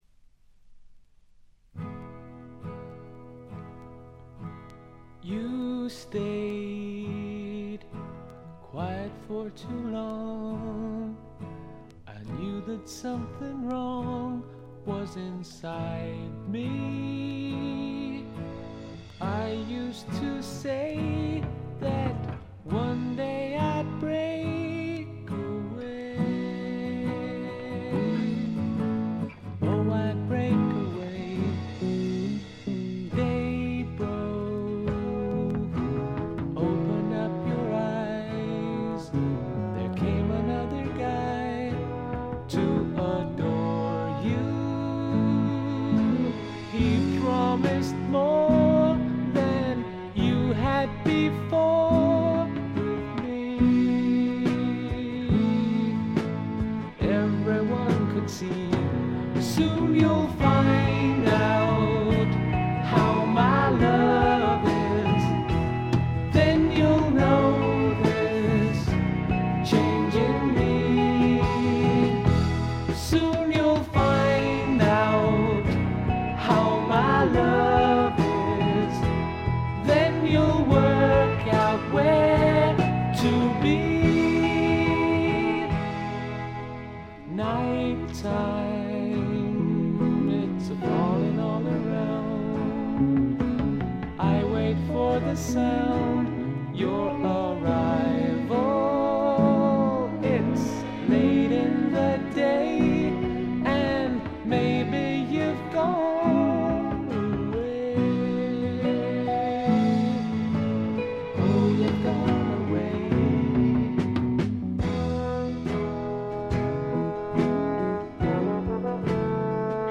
静音部で軽微なチリプチが少し。
英国の男性デュオ
フォーク・ロックを基調に栄光の英国ポップのエッセンスをたっぷりふりかけた音作りです。
超英国的な陰影に満ちたしめっぽさと、切ないメロディの甘酸っぱいサウンド。
試聴曲は現品からの取り込み音源です。